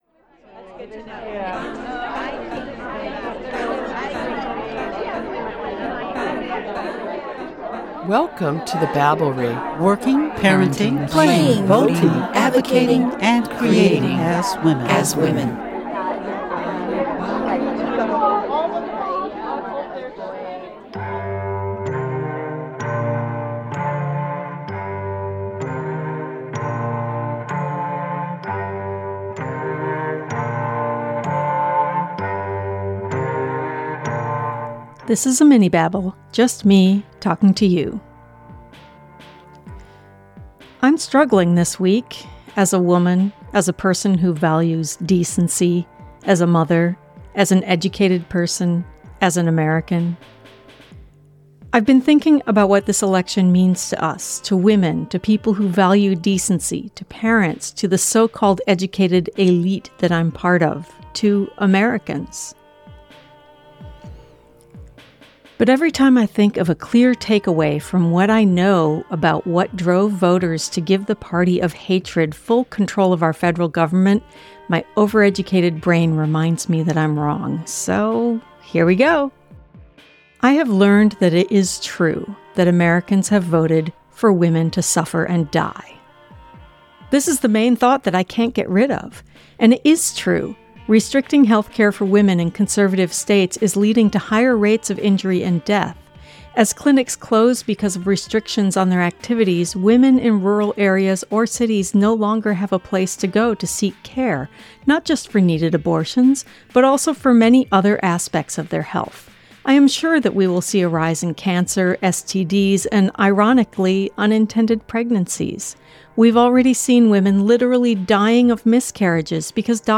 This is a Minibabble, just me talking to you. I’m struggling this week.